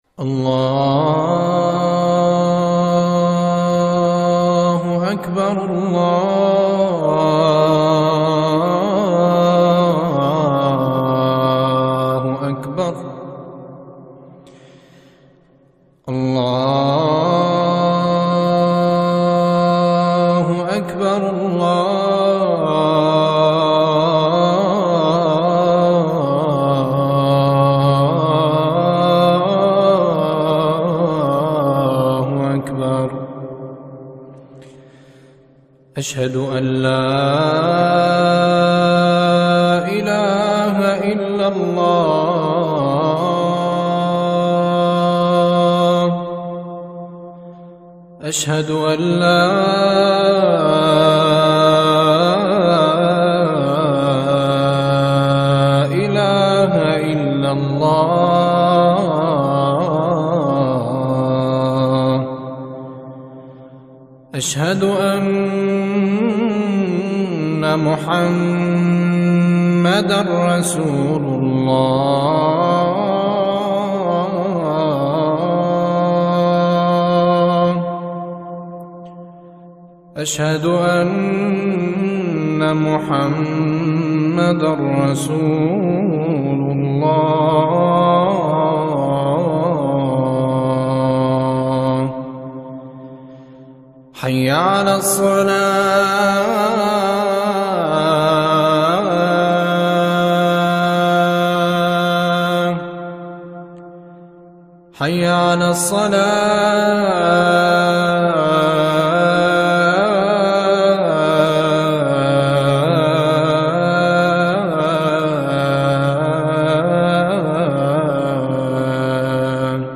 Islam Sobhi Adhan
islam-sobhi-adhan.mp3